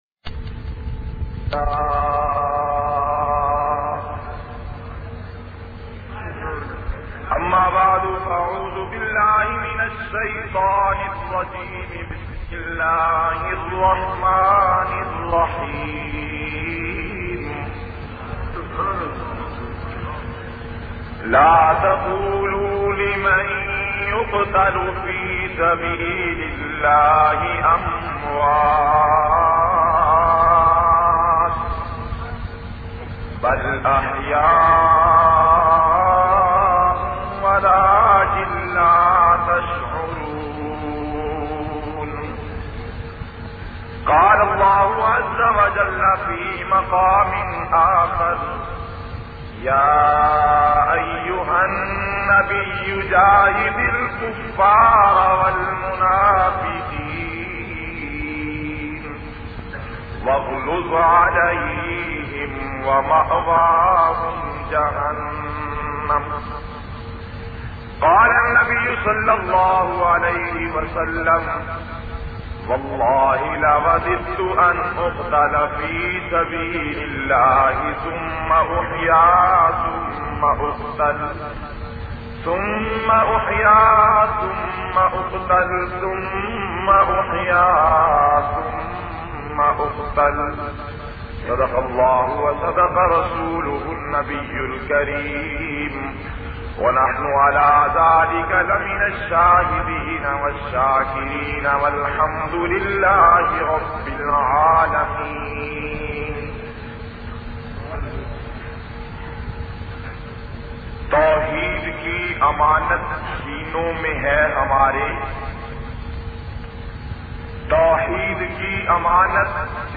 56- jhang-man-pahla-khitab-e-juma.mp3